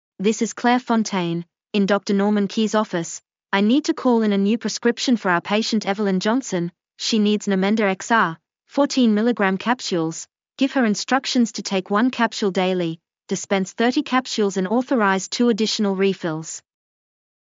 Practice Taking Verbal Prescriptions